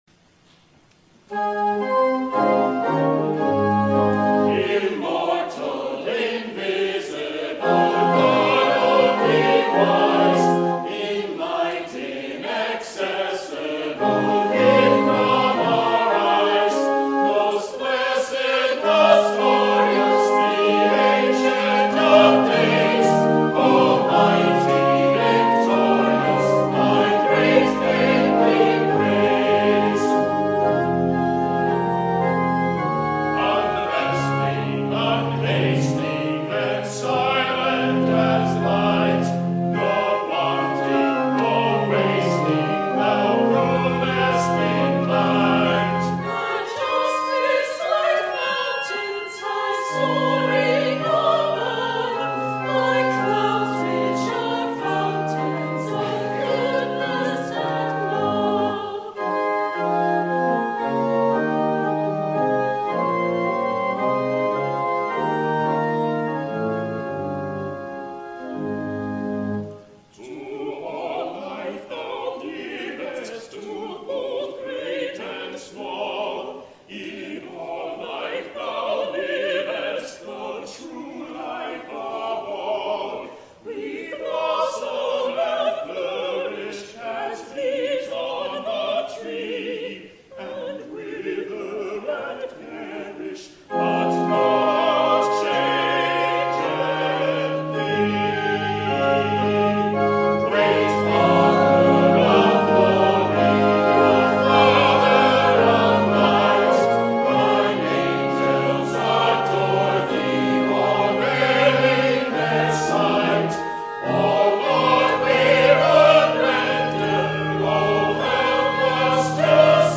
The Second Reformed Chancel Choir sings "Immortal Invisible" my Eric Thiman